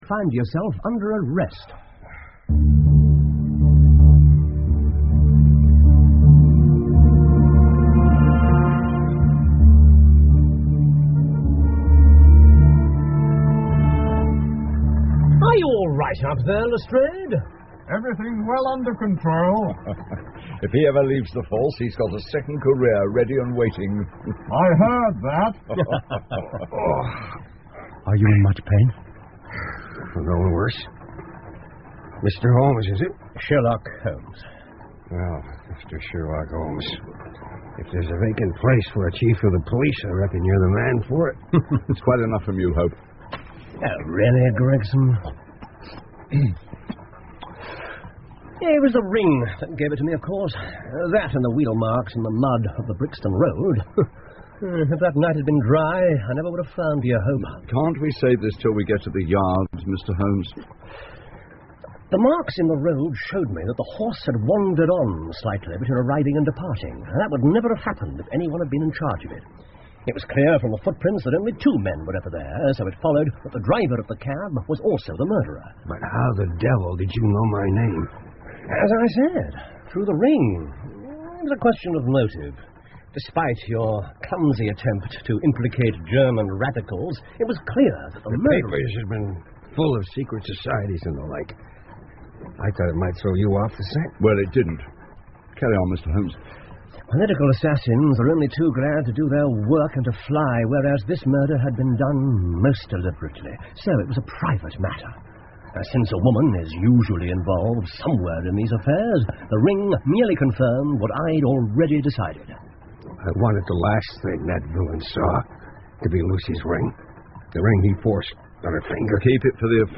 福尔摩斯广播剧 A Study In Scarlet 血字的研究 16 听力文件下载—在线英语听力室